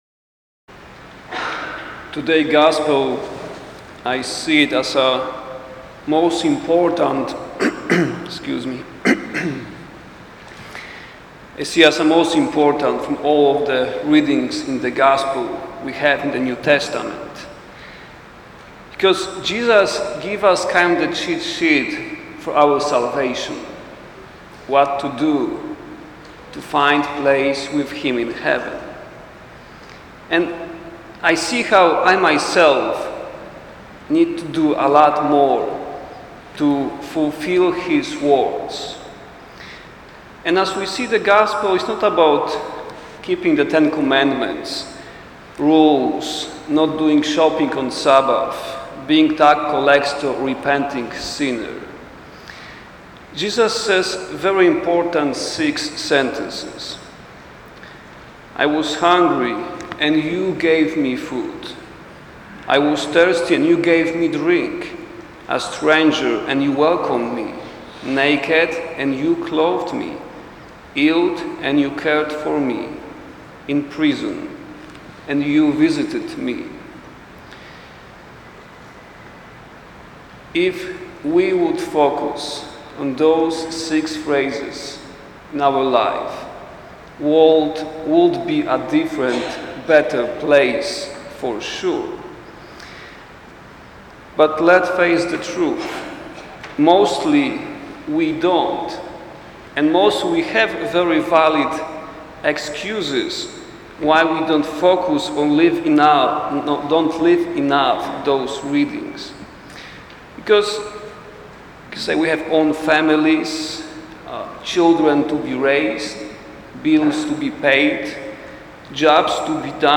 Cheat Sheet from our Savior. Christ the King Homily